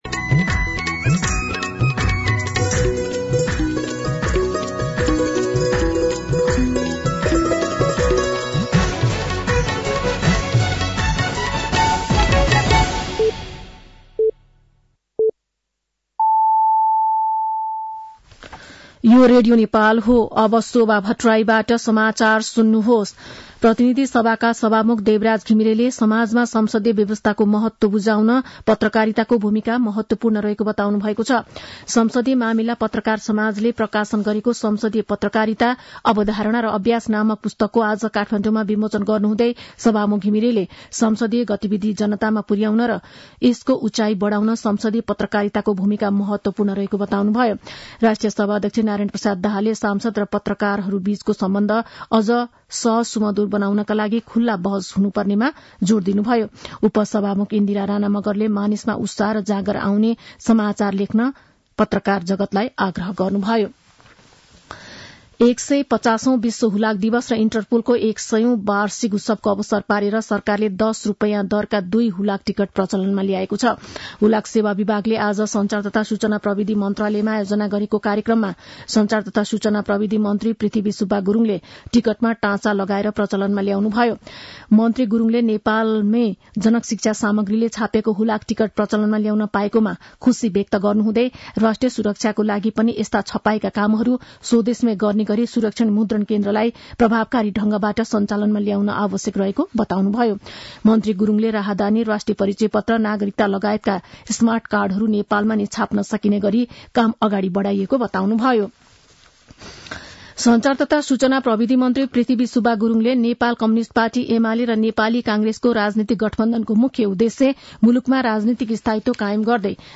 साँझ ५ बजेको नेपाली समाचार : २ पुष , २०८१
5-PM-Nepali-News-9-1.mp3